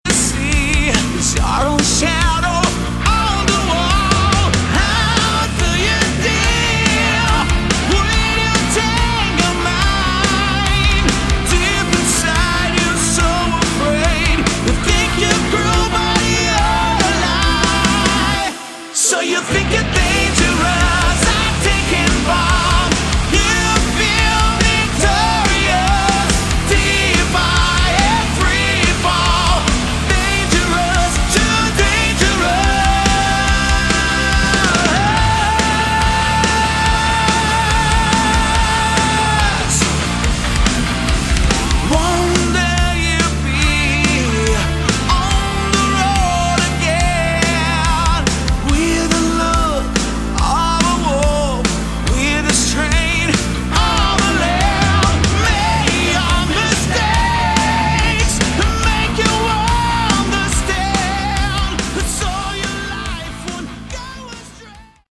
Category: Melodic Hard Rock
Guitars
Drums
Bass
Backing vocals